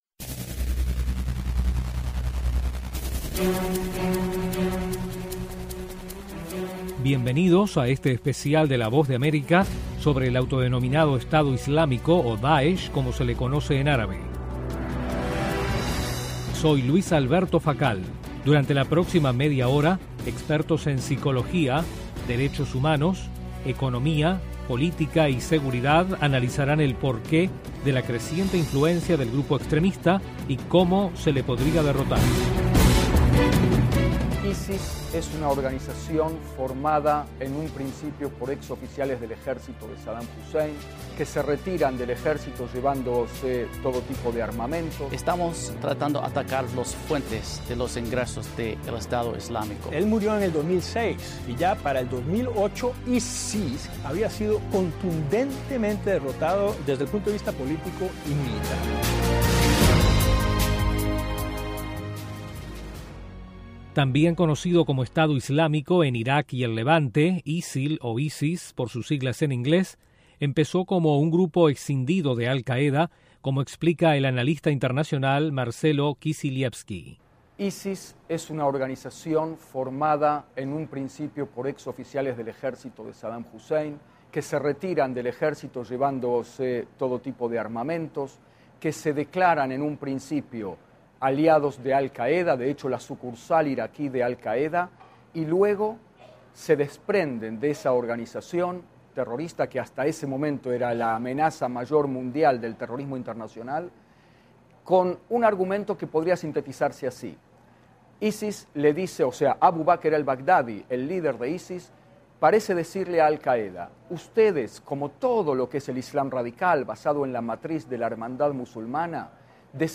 [Duración 30 minutos con cortes para comerciales].